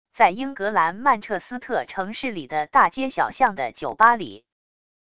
在英格兰曼彻斯特城市里的大街小巷的酒吧里wav音频